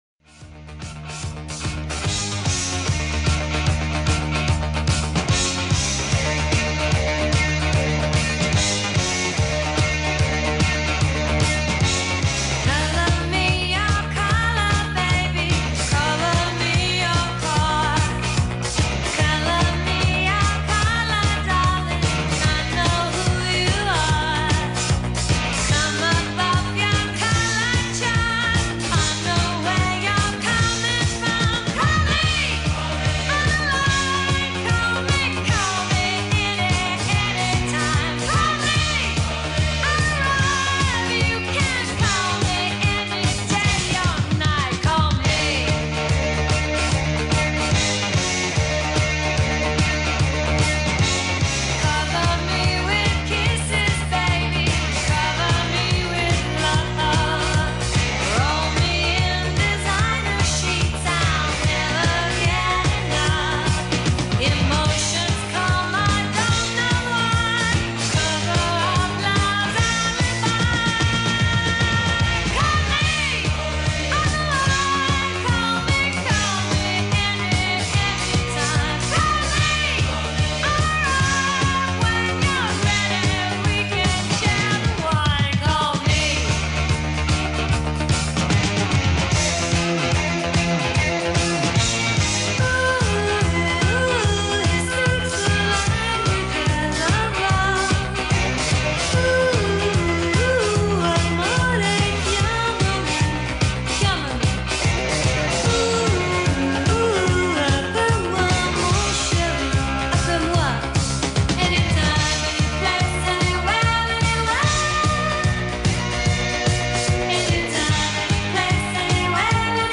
148 Bpm - Key : DmSourceTélécharger PDF Book